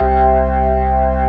55o-org06-C2.aif